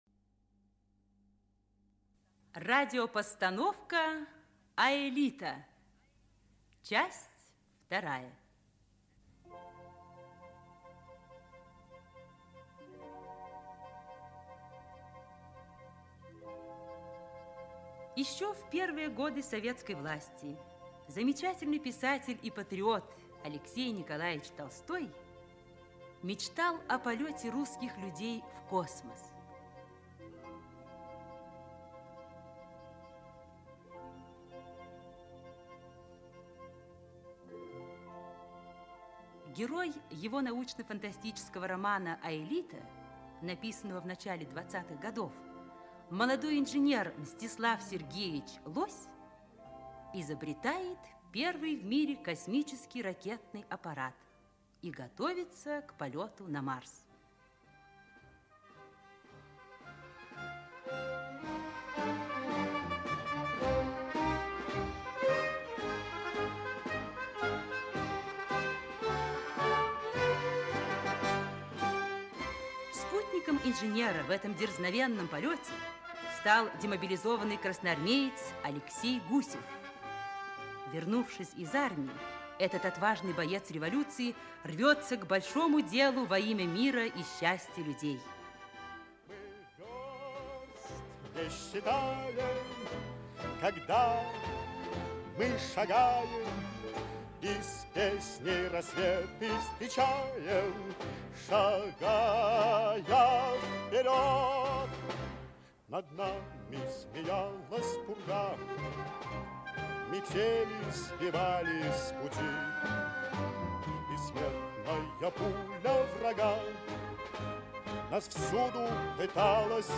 Аудиокнига Аэлита.
Aудиокнига Аэлита. Часть 2 Автор Алексей Толстой Читает аудиокнигу Актерский коллектив.